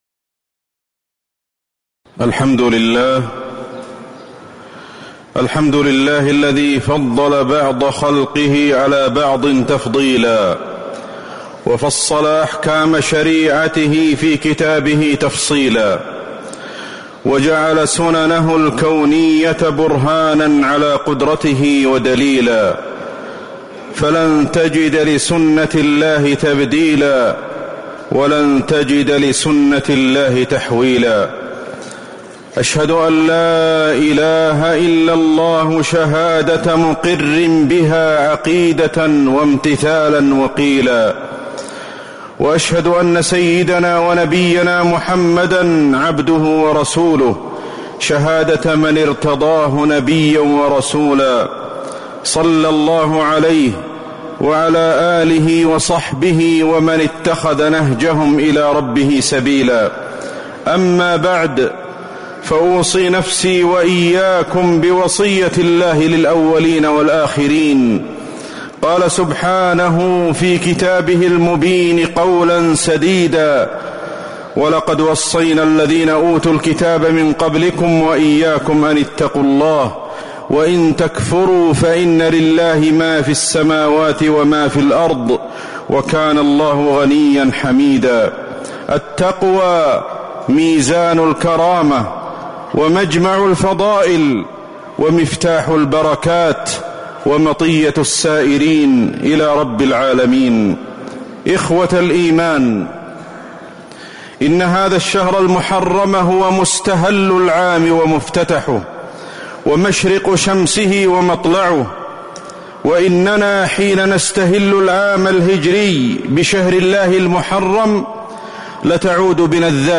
تاريخ النشر ١٠ محرم ١٤٤٥ هـ المكان: المسجد النبوي الشيخ: فضيلة الشيخ أحمد بن علي الحذيفي فضيلة الشيخ أحمد بن علي الحذيفي حادثة الهجرة النبوية الميمونة